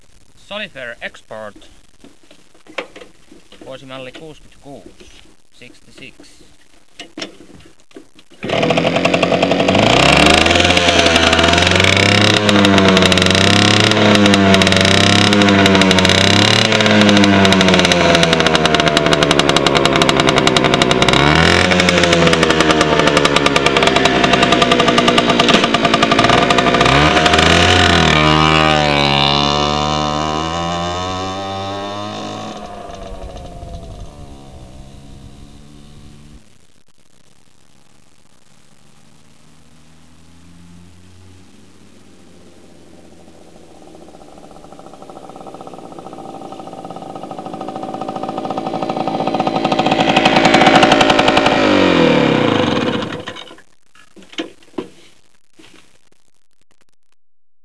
Solifer Export äänessä erikseen